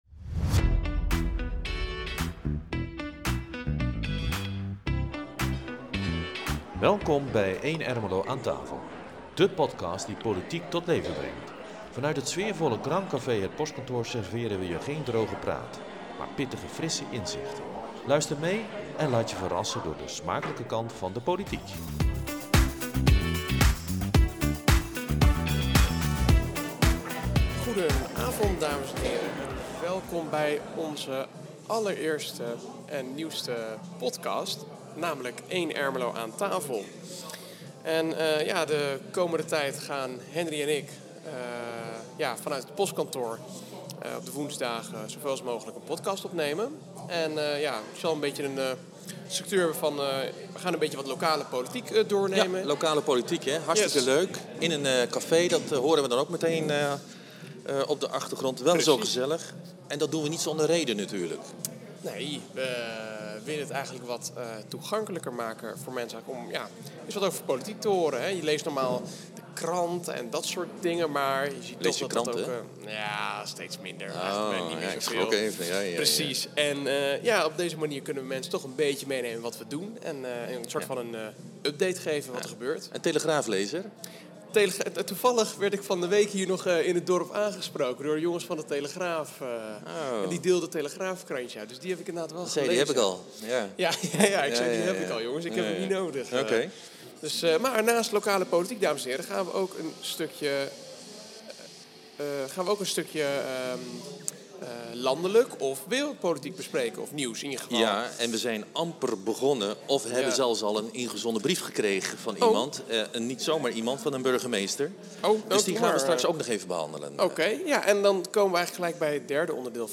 vanuit het Postkantoor